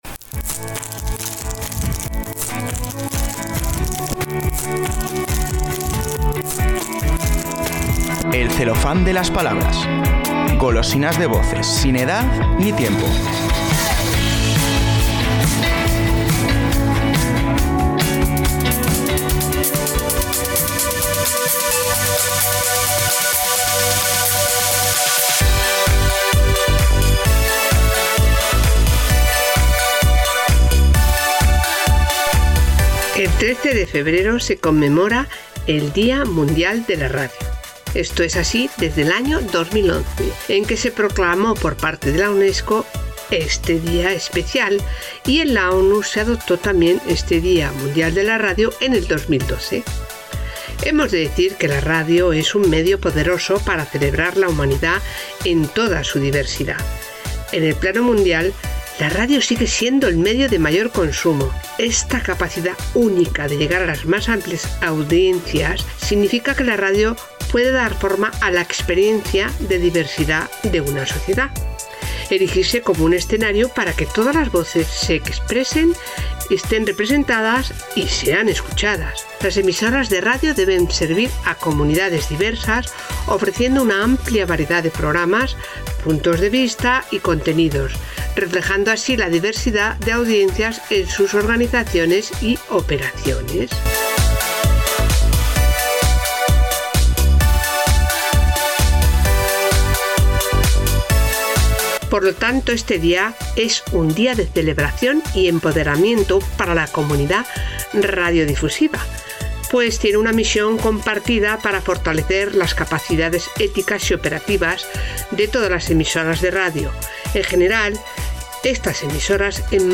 Con su voz cercana y su sensibilidad poética